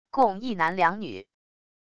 共一男两女wav音频